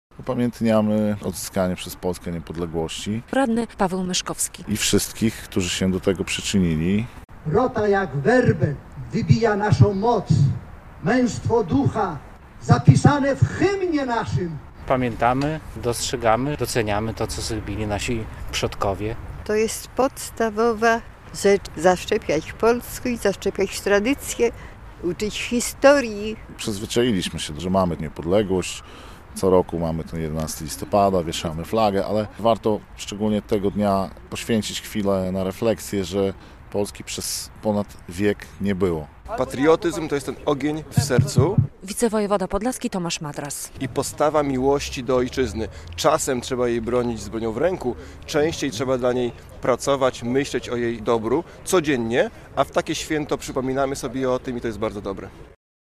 Przemarsz pod hasłem "Wolność krzyżami się mierzy" w Białymstoku - relacja